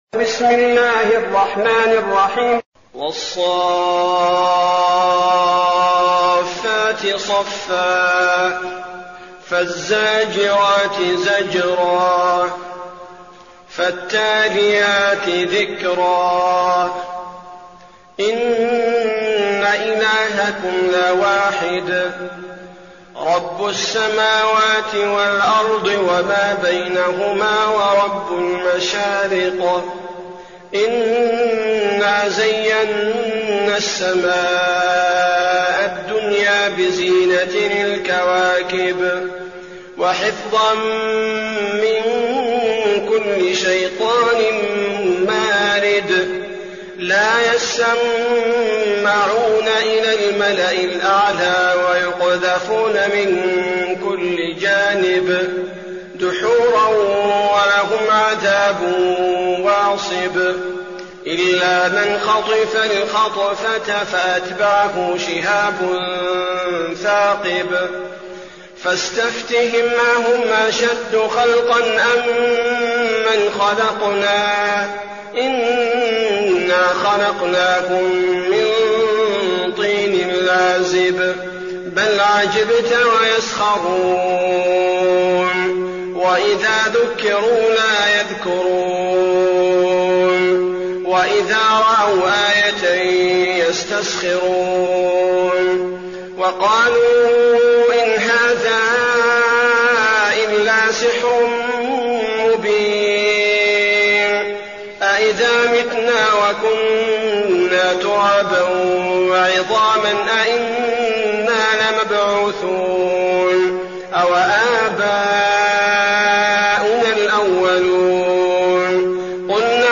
المكان: المسجد النبوي الشيخ: فضيلة الشيخ عبدالباري الثبيتي فضيلة الشيخ عبدالباري الثبيتي الصافات